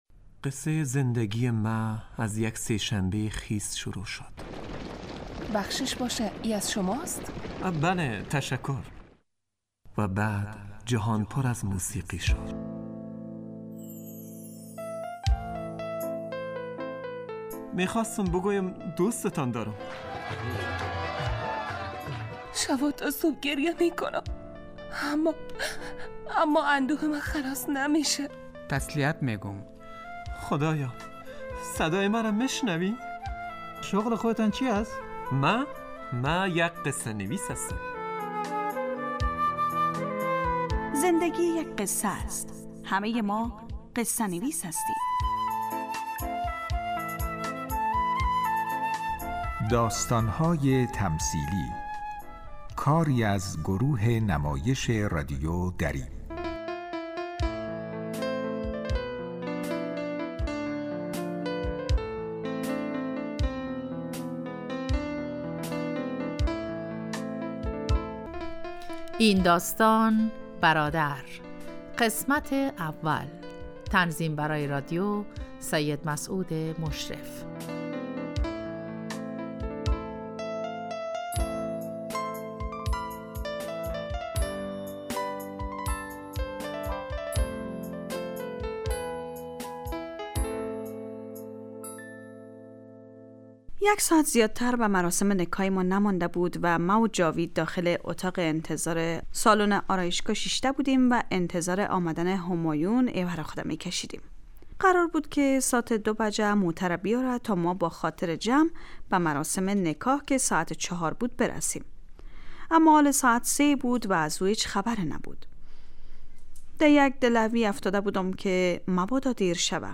داستانهای تمثیلی نمایش 15 دقیقه ای هست که از شنبه تا پنج شنبه ساعت 03:20 عصربه وقت افغانستان پخش می شود.